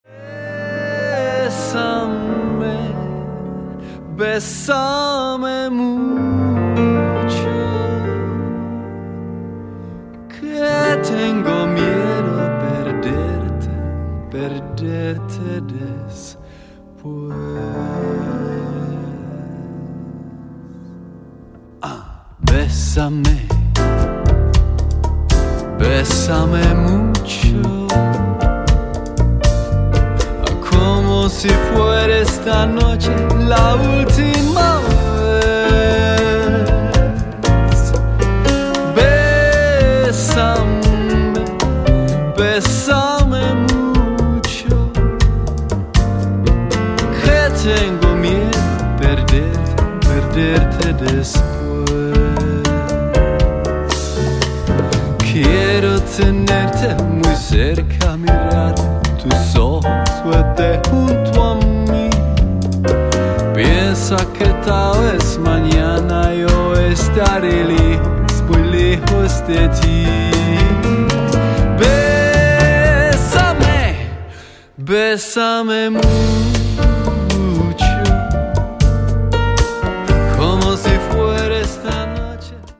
světoznámá dueta, swing, evergreeny, oldies